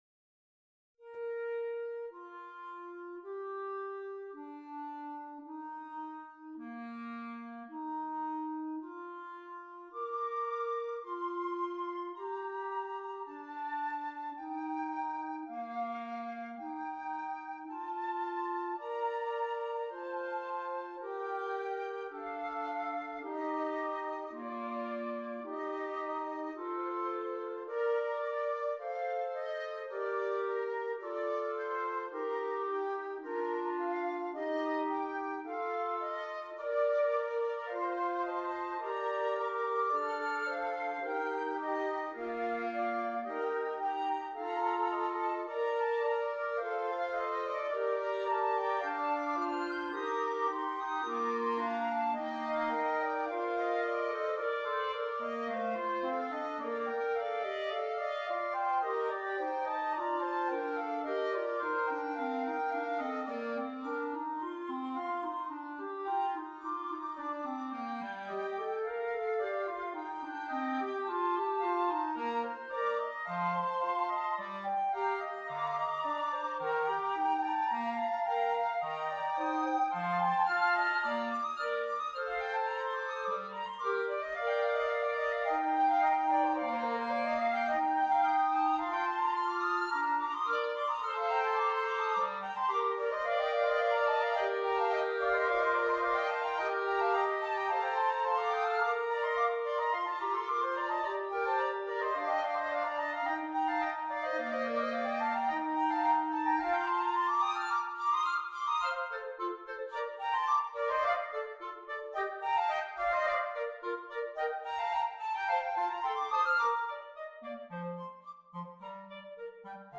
2 Flutes, 2 Clarinets
Difficulty: Medium-Difficult Order Code